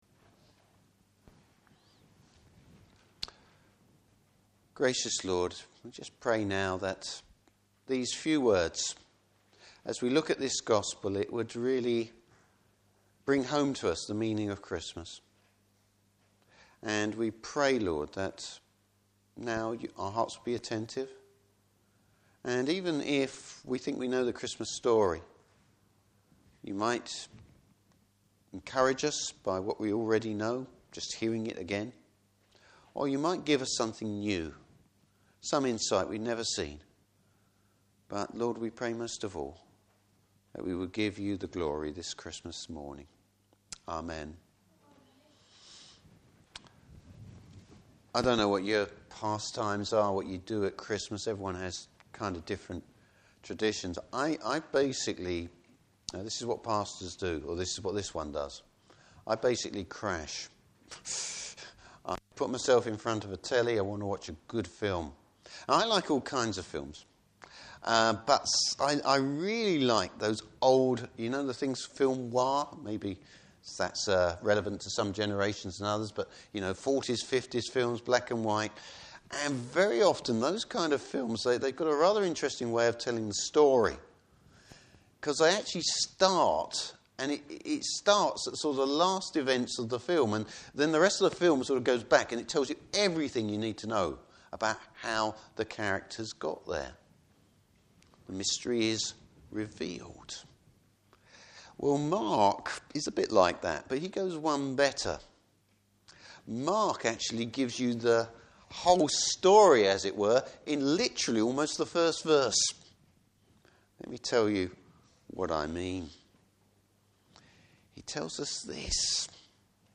Service Type: Christmas Day Morning Service.